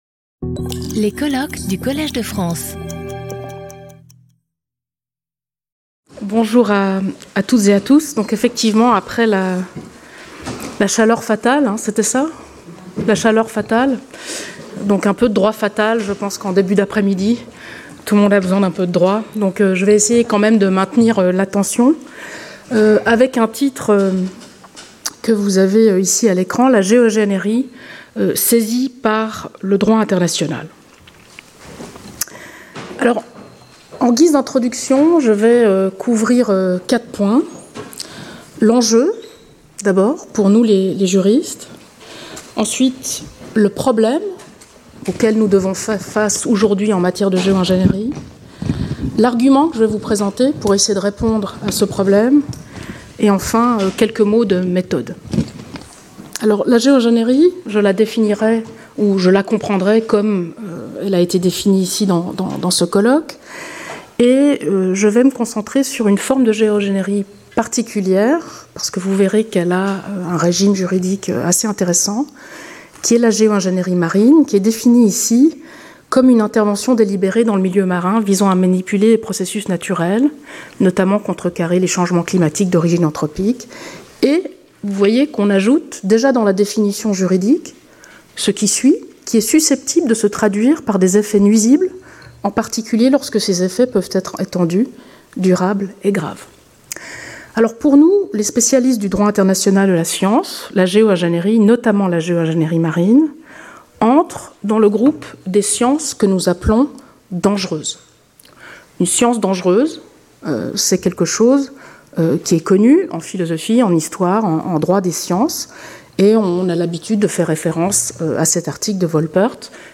Symposium